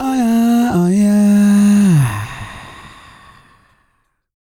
E-CROON 3051.wav